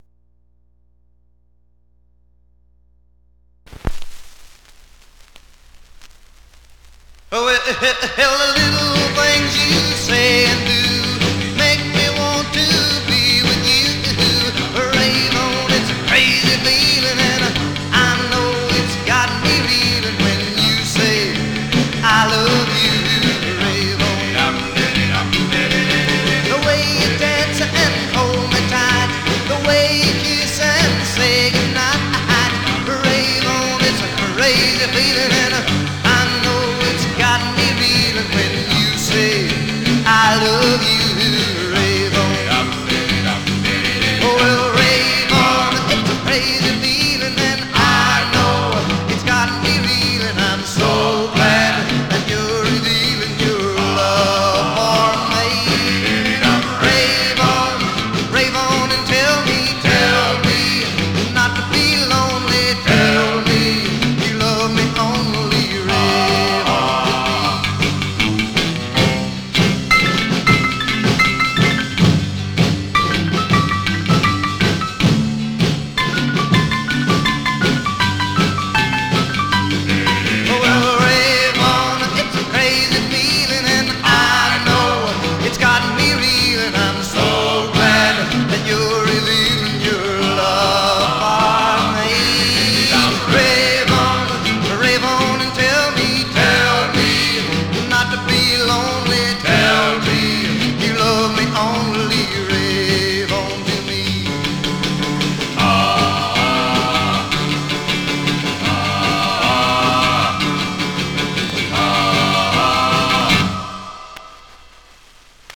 Much surface noise/wear
Mono
Rockabilly